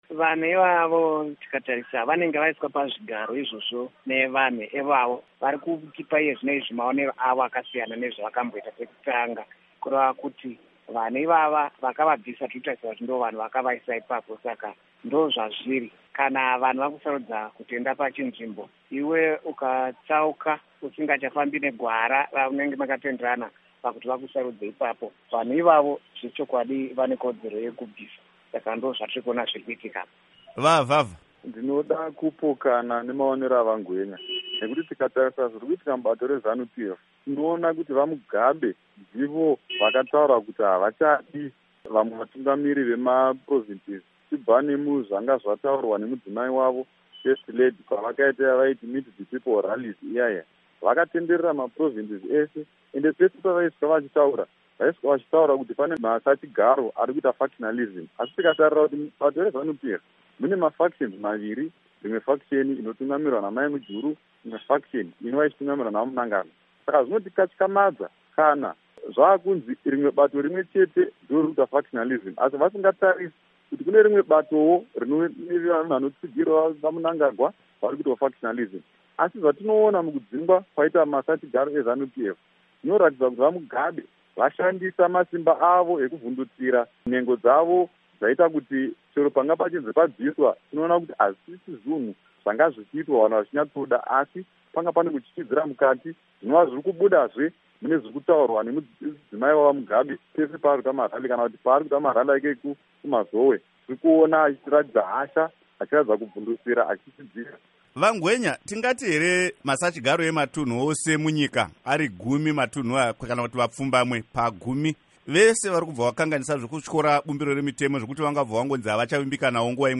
Hurukuro naVaJason Machaya